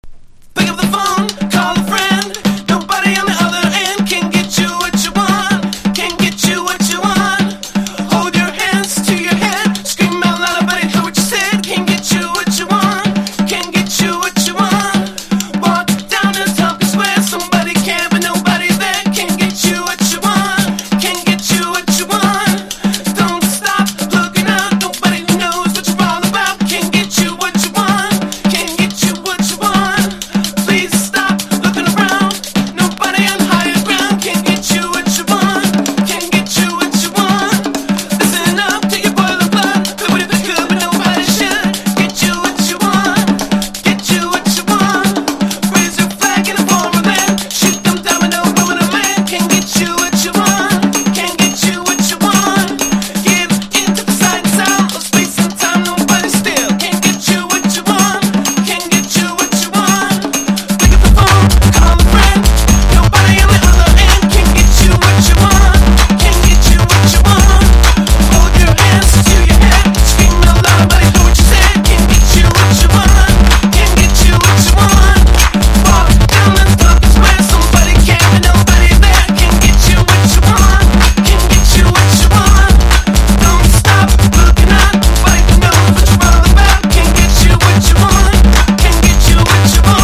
INDIE DANCE
ELECTRO